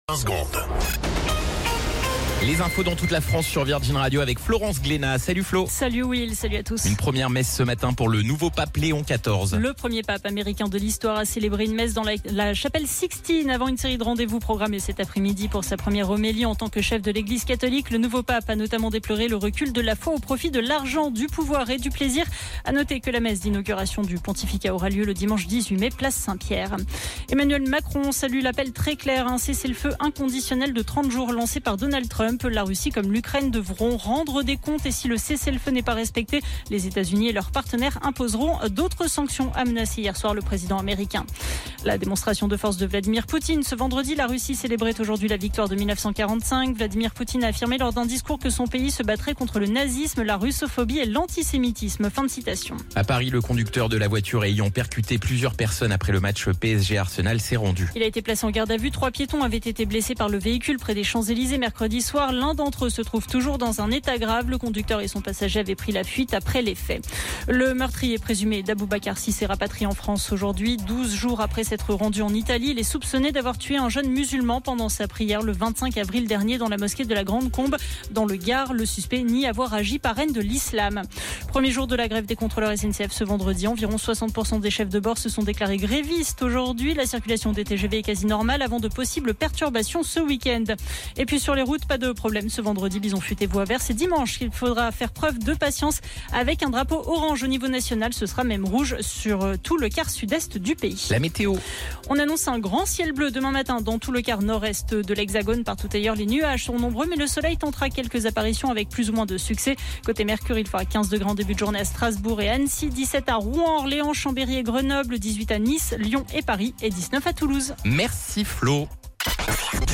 Flash Info National 09 Mai 2025 Du 09/05/2025 à 17h10 .